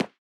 Walk01.wav